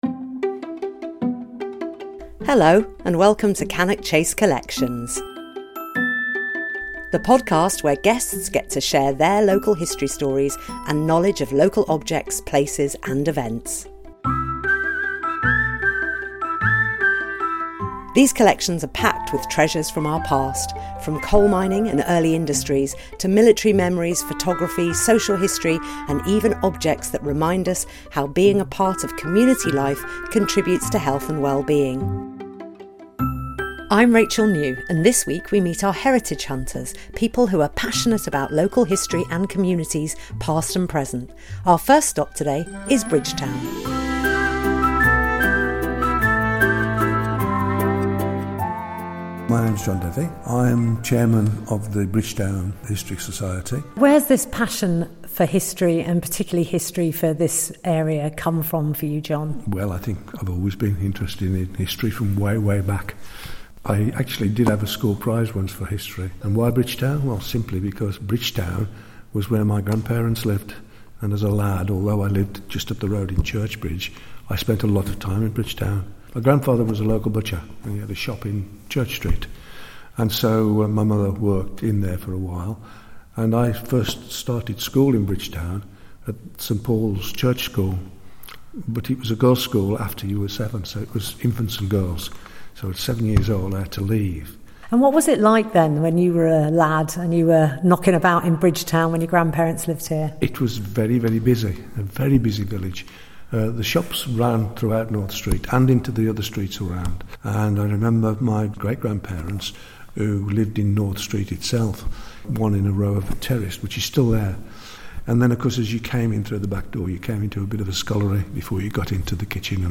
Focusing on the Hednesford War Memorial, an expert reveals more stories behind this important local landmark. We also hear from community heritage groups, Cheslyn Hay & Bridgtown Local History Society and Hednesford in Partnership, about how they are inspired to undertake their own research projects and ongoing efforts to keep Cannock Chase’s history alive.